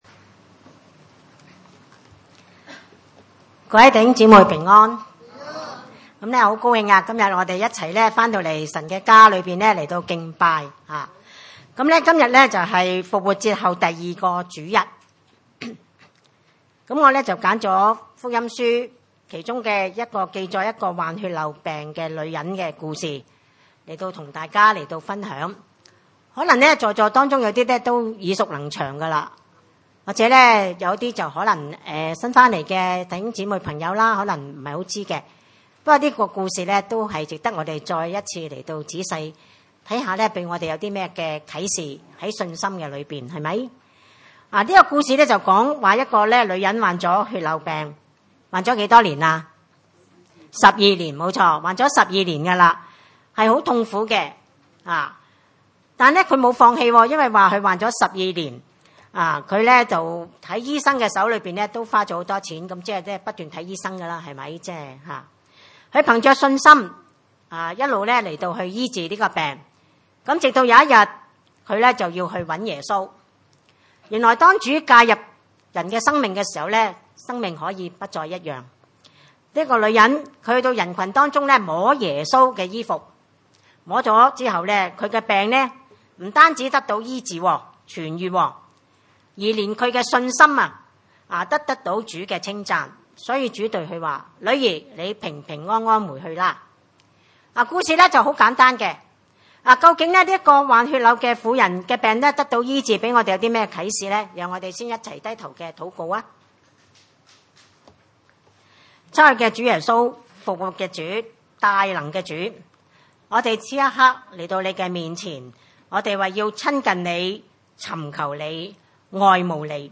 可5:25-34 崇拜類別: 主日午堂崇拜 25 有 一 個 女 人 ， 患 了 十 二 年 的 血 漏 ， 26 在 好 些 醫 生 手 裡 受 了 許 多 的 苦 ， 又 花 盡 了 他 所 有 的 ， 一 點 也 不 見 好 ， 病 勢 反 倒 更 重 了 。